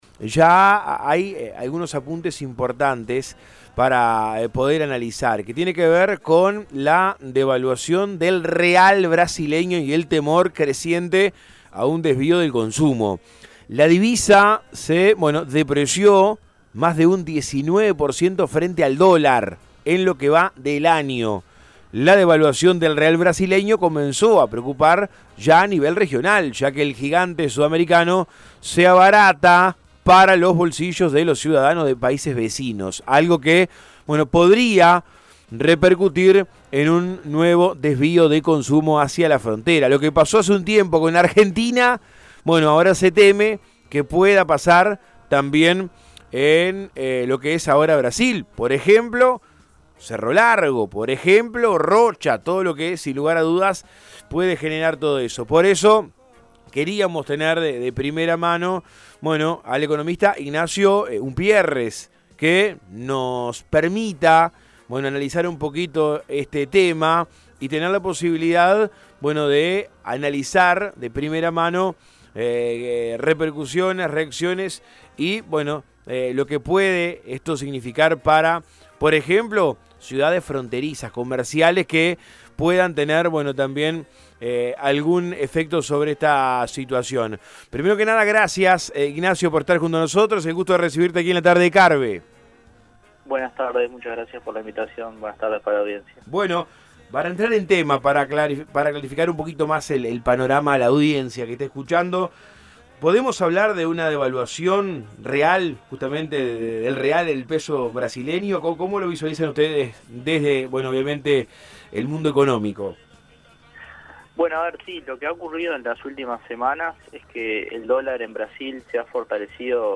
La devaluación del real brasileño comenzó a preocupar a muchos comerciantes de la frontera este, ya que Brasil se abarata para los bolsillos de los uruguayos y vecinos de Cerro Largo o el Chuy La creciente diferencia cambiaria llevaría a que muchos uruguayos podrían preferir hacer su compras y vacacionar del otro lado de la frontera. En diálogo con Todo Un País